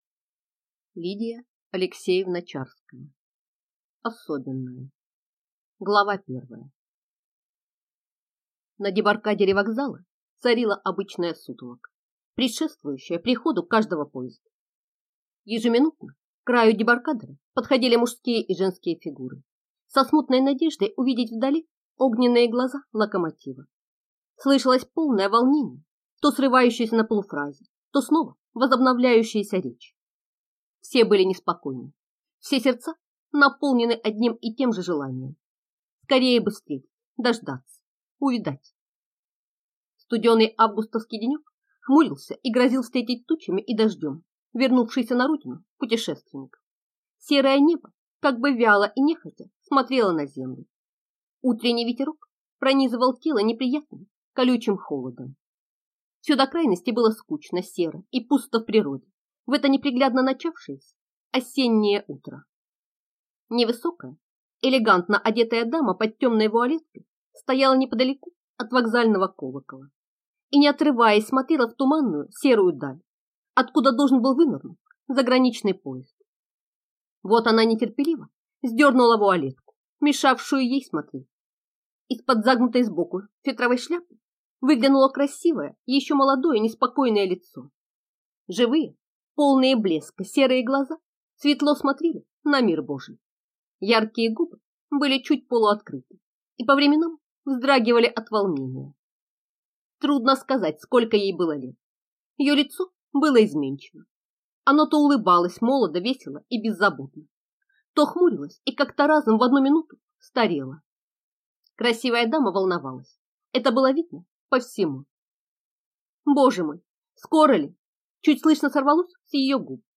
Аудиокнига Особенная | Библиотека аудиокниг